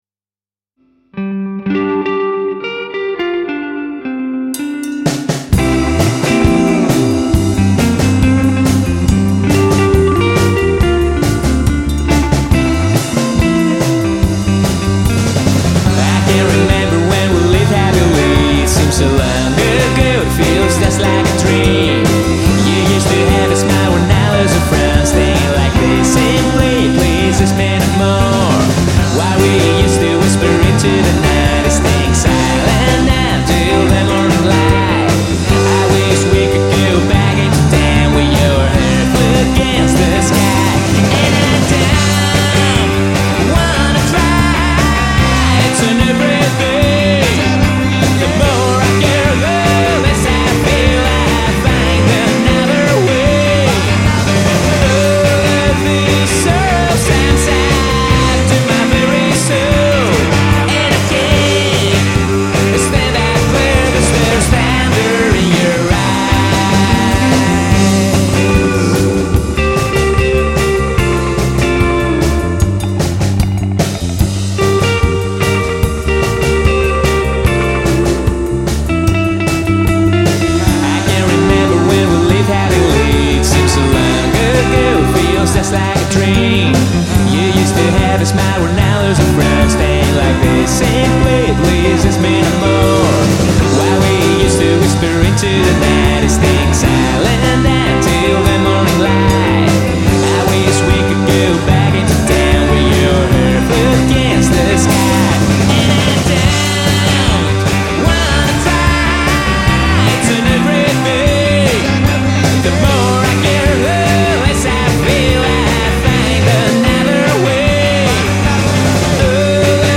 in 2016 and although being a garage rock band at its core
such as surf and noise rock.